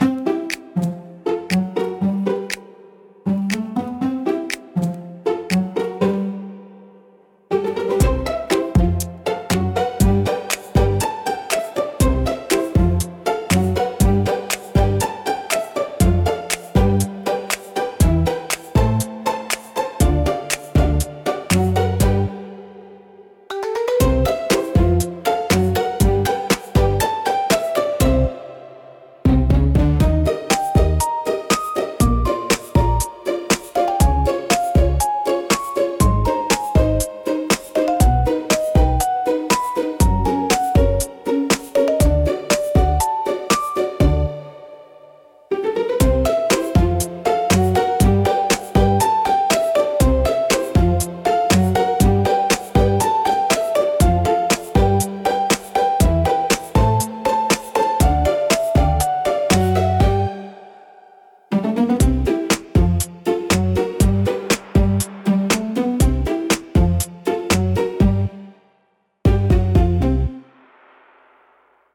オリジナルのピチカートは、ピチカート奏法のストリングスを主体にした穏やかで可愛らしい曲調が特徴です。